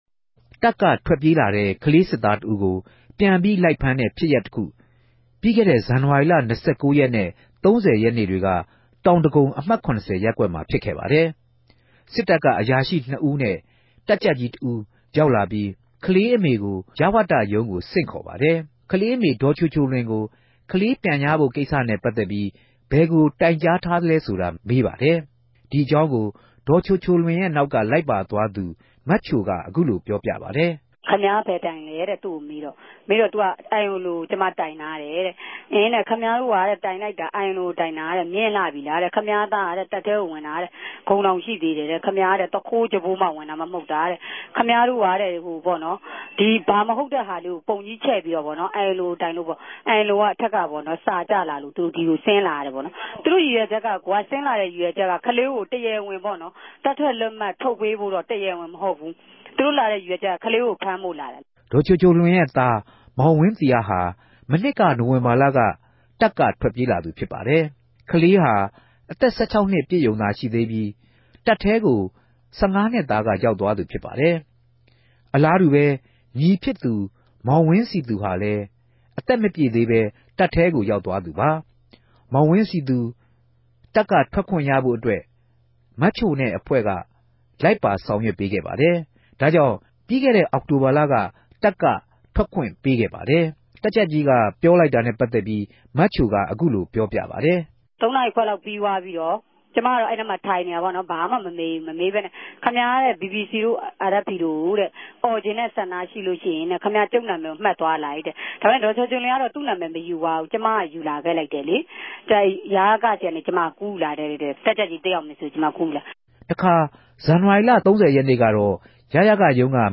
မေးူမန်း တင်ူပထားပၝတယ်၊၊